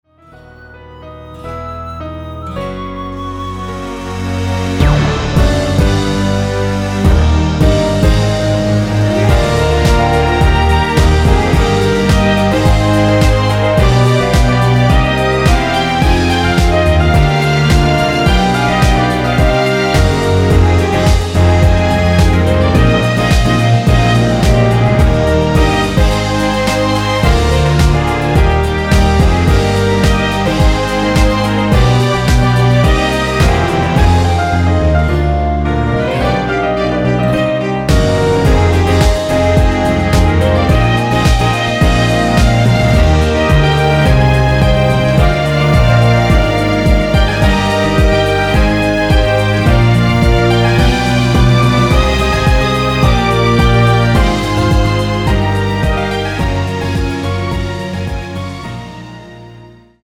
1절후 후렴구로 진행 되게 편곡 하였습니다.(가사및 미리듣기 참조)
◈ 곡명 옆 (-1)은 반음 내림, (+1)은 반음 올림 입니다.
멜로디 MR이라고 합니다.
앞부분30초, 뒷부분30초씩 편집해서 올려 드리고 있습니다.
중간에 음이 끈어지고 다시 나오는 이유는